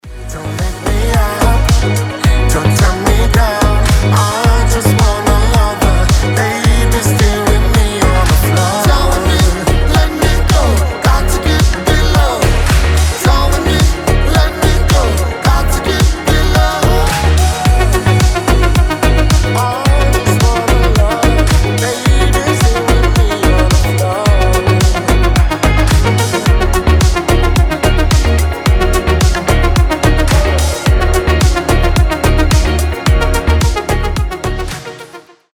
• Качество: 320, Stereo
nu disco
Легкий мотив на телефон